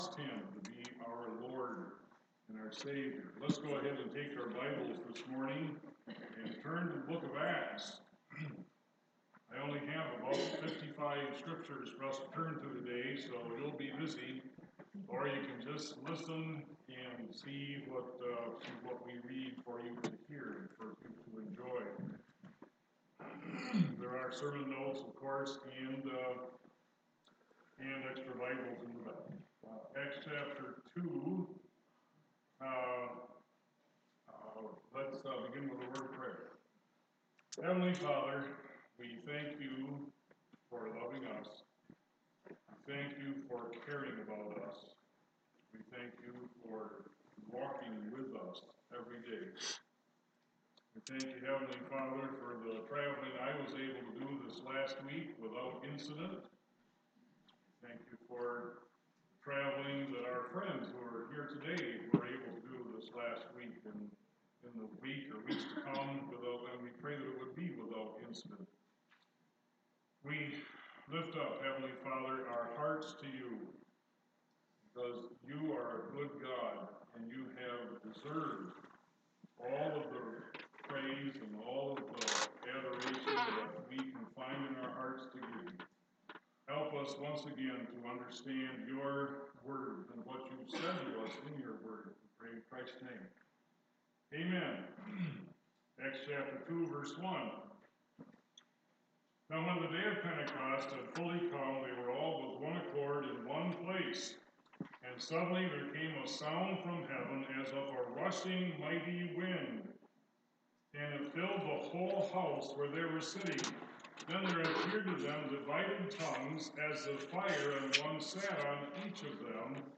Sunday Morning Message – Battle of 1000 Temples Acts 2 Date 6-30-19